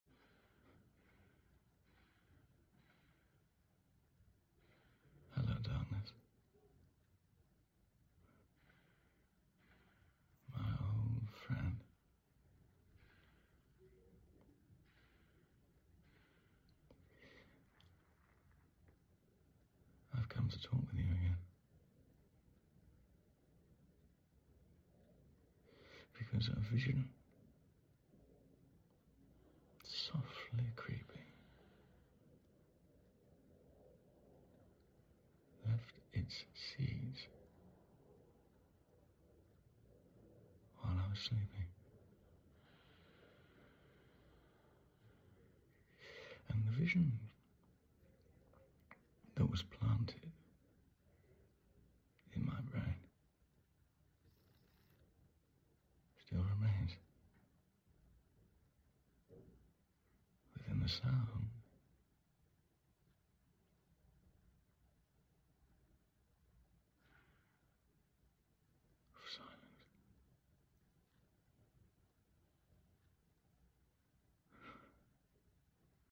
🎭 Dramatic Monologue: The Sound sound effects free download